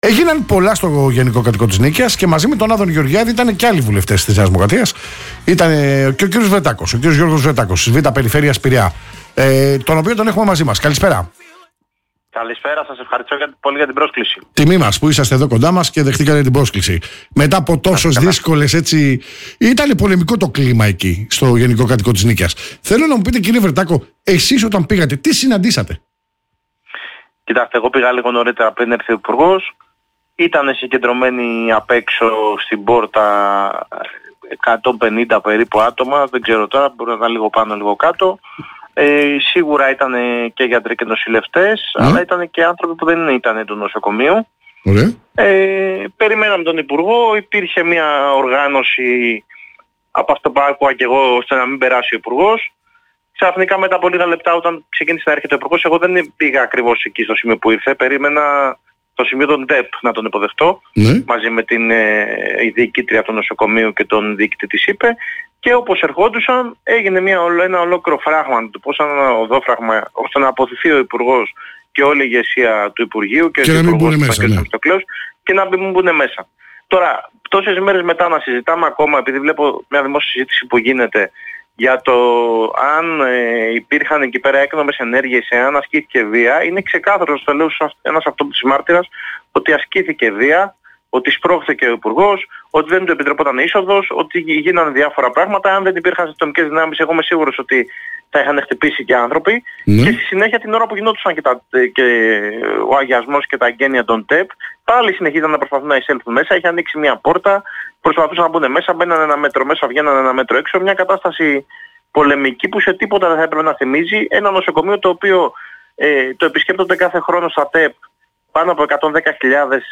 Στον αέρα του Politica 89,8 και στην εκπομπή «Όλα Μαζί» μίλησε ο Γιώργος Βρεττάκος για τα επεισόδια που σημειώθηκαν στο Γενικό Κρατικό Νοσοκομείο Νίκαιας,